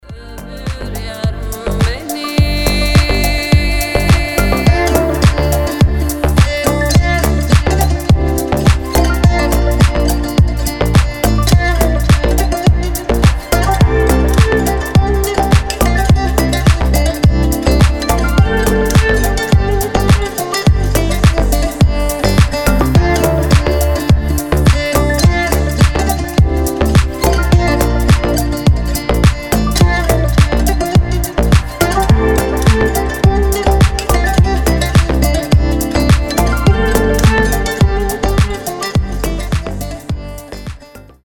• Качество: 320, Stereo
deep house
мелодичные
восточные